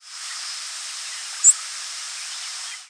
Short rising seeps
White-crowned Sparrow ex1 ex2